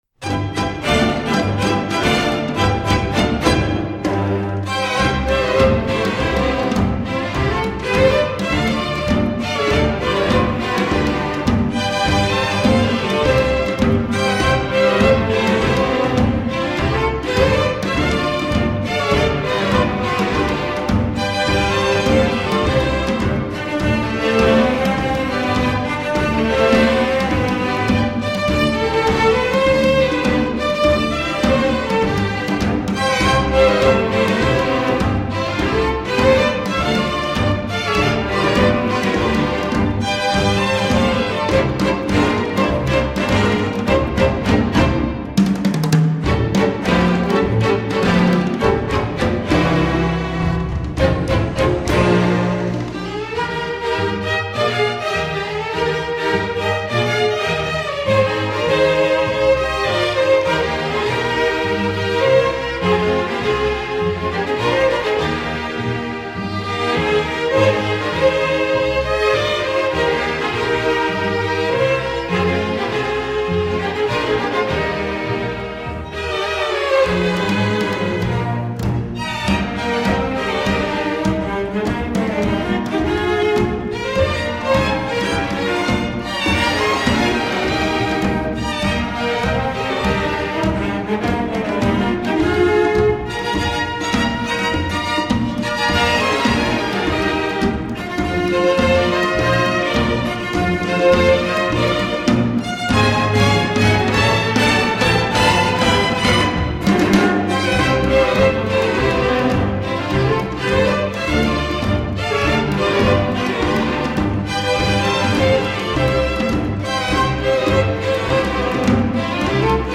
Compositeur: traditional
Concert Band